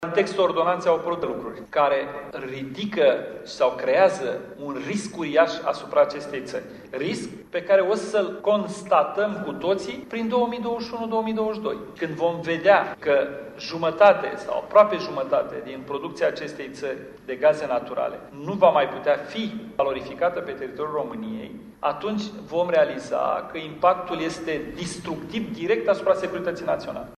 Declaraţia a fost făcută la Parlament, în cadrul unor dezbateri pe tema resurselor naturale ale României şi a posibilităţilor de valorificare a acestora: